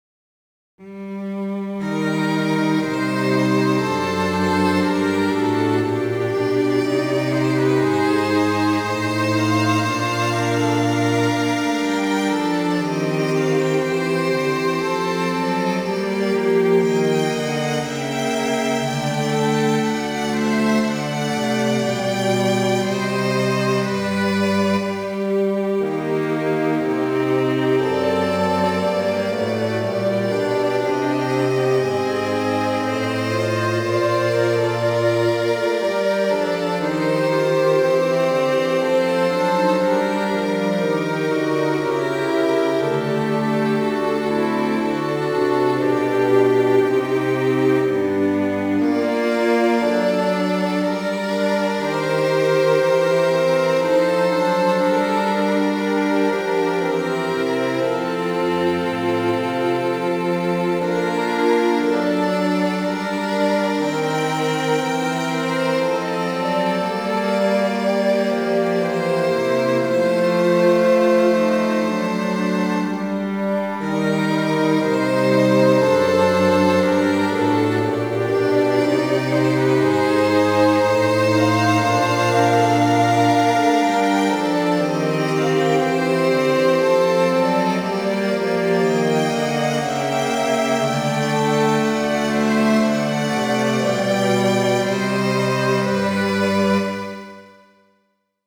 ♪Instrumental ensemble (Rc-Fl-Clg-Fg-St)